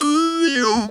Index of /90_sSampleCDs/USB Soundscan vol.18 - Funky Vocals [AKAI] 1CD/Partition C/02-TB MELODY